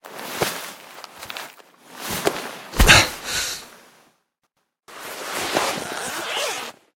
medkit_use.ogg